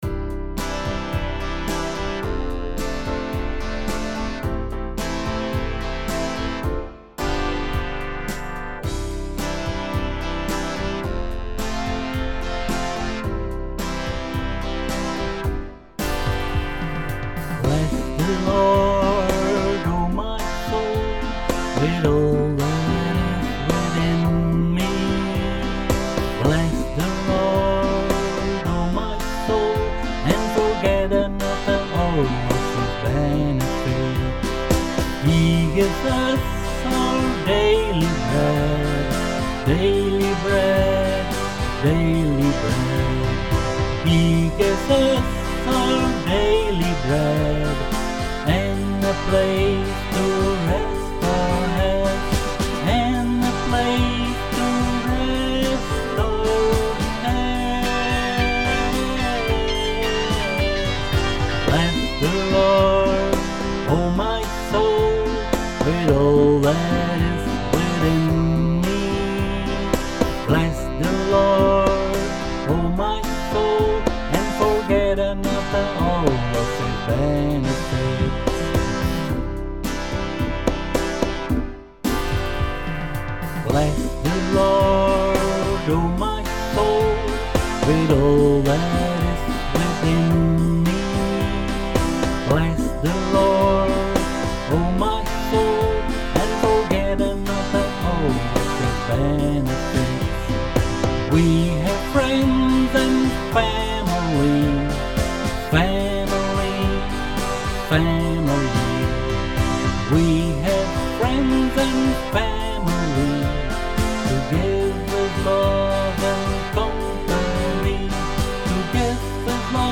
a spirited, joyous song of thanksgiving